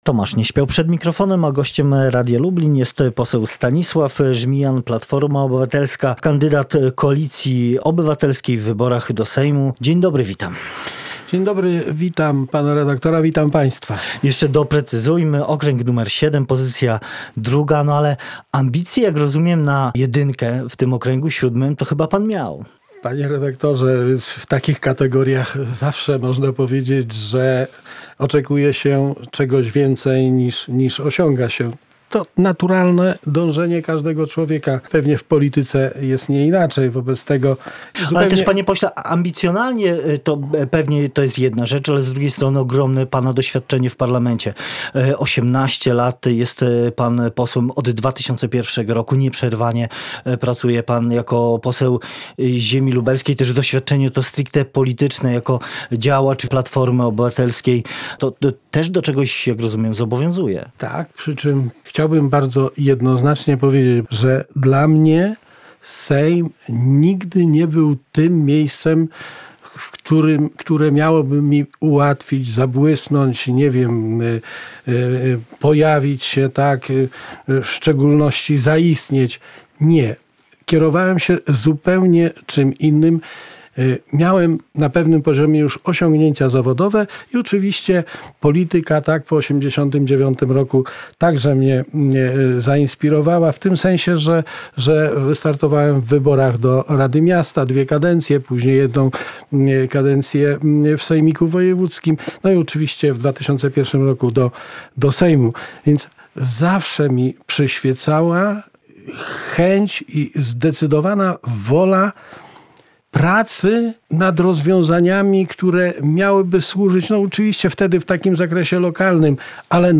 Opóźnienia w realizacji inwestycji kolejowych i drogowych, czy problemy w funkcjonowaniu programu mieszkaniowego – to główne zarzuty, jakie pod adresem kierownictwa Ministerstwa Infrastruktury kieruje poseł Stanisław Żmijan (na zdjęciu) z Koalicji Obywatelskiej, który był gościem porannej rozmowy w Radiu Lublin.